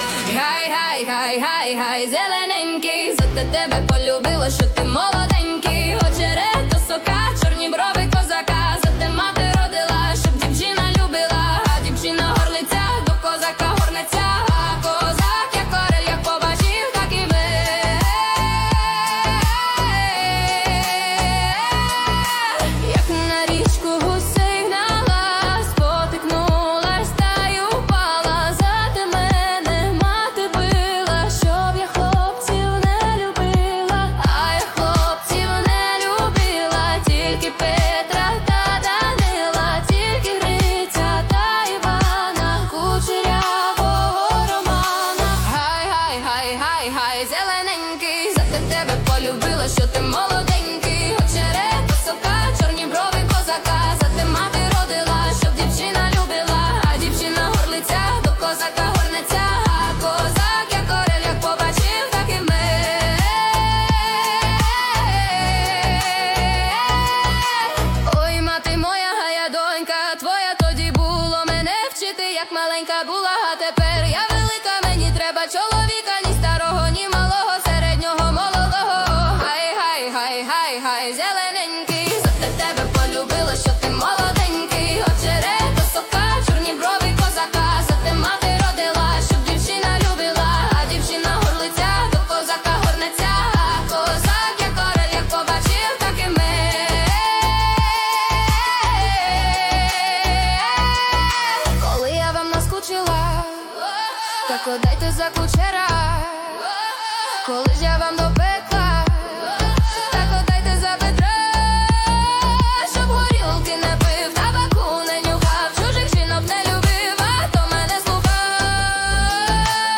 Качество: 320 kbps, stereo
Украинские писни 2025, Ремиксы
DJ House Bass Ethno R&B